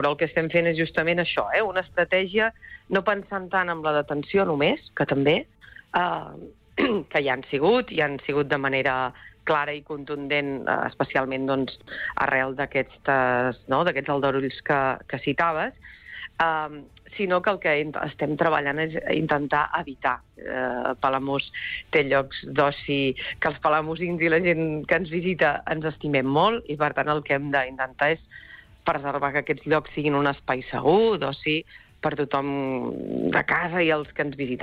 L’alcaldessa de Palamós, Maria Puig, ha valorat positivament les primeres setmanes al càrrec, en una entrevista concedida al Supermatí de Ràdio Capital.